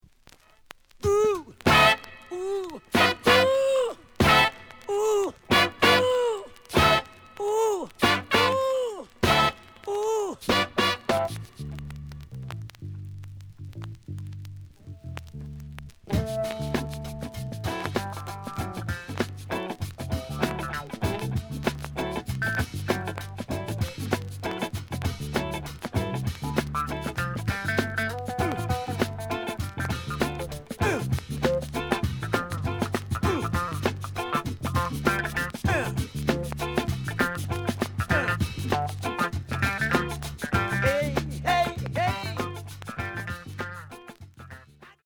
The listen sample is recorded from the actual item.
●Genre: Funk, 70's Funk